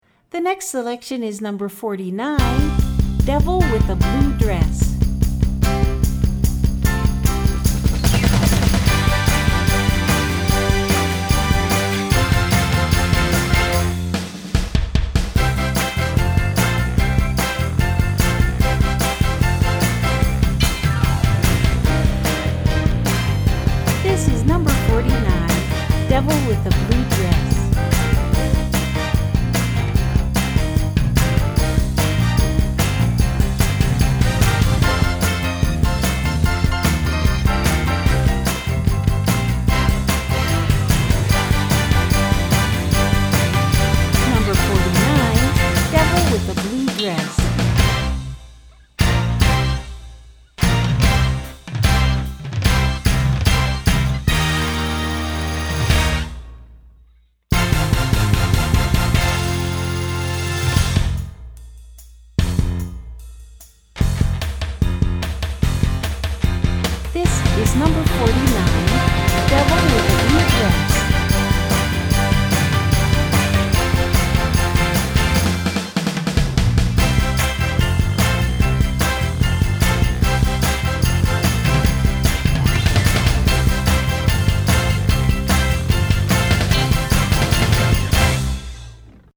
1:25 Style: Rock Category